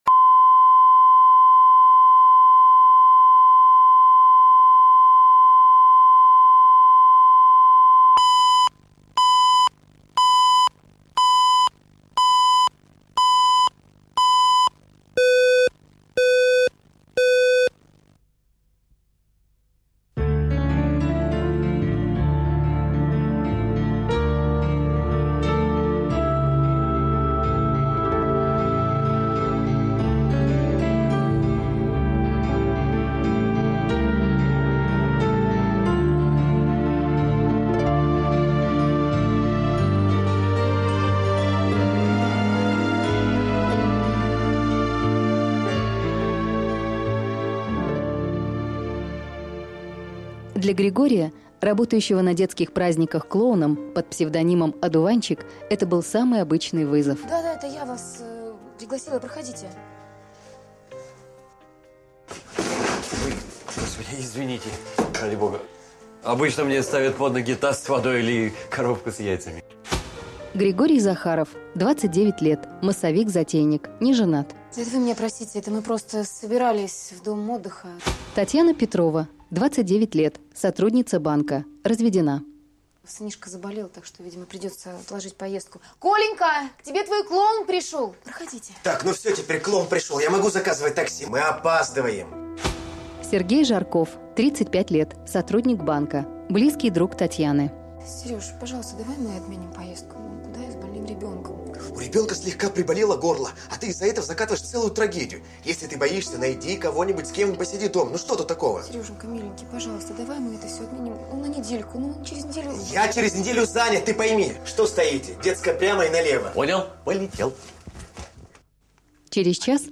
Аудиокнига Одуванчик | Библиотека аудиокниг